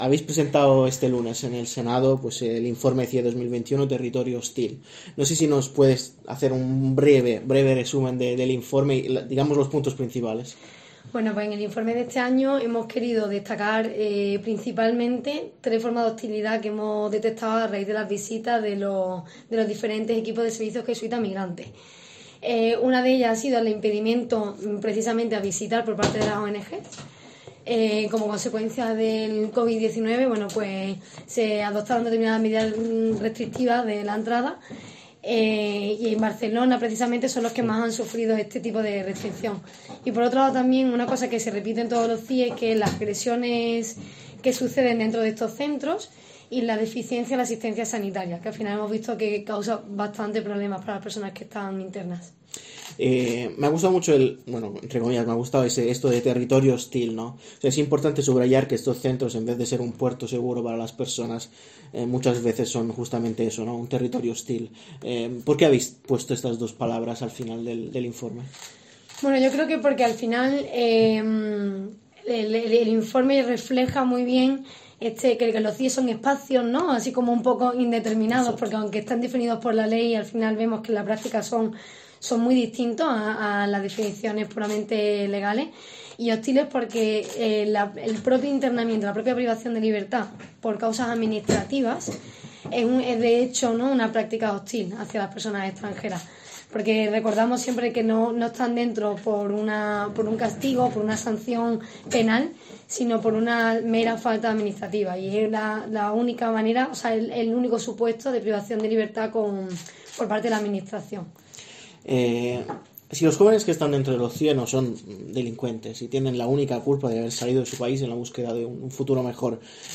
Esta semana se ha presentado el Informe CIE 2021 y algunos de los autores han visitado la redacción de ECCLESIA para explicar las principales claves del documento